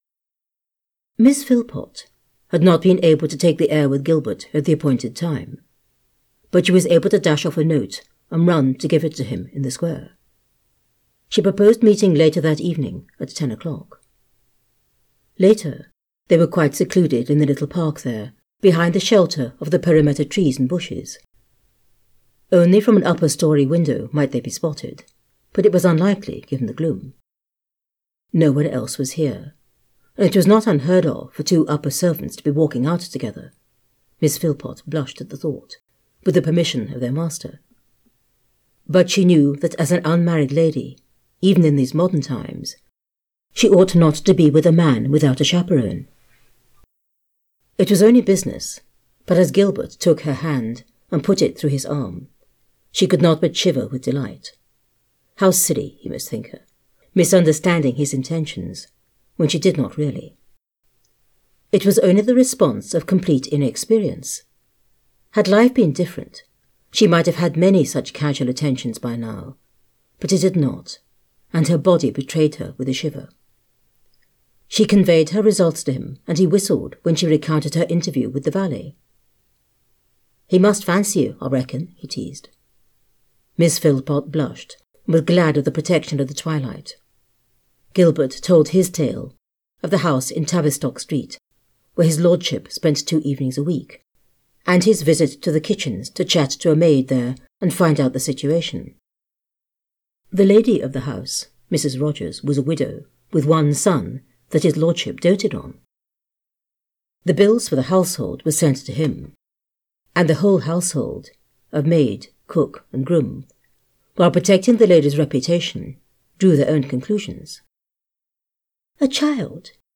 The Francine and the Art of Transformation audiobook is available on Amazon, Audible and iTunes.